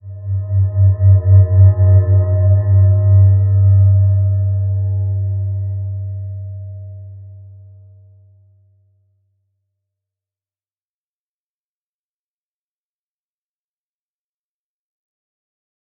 Slow-Distant-Chime-G2-f.wav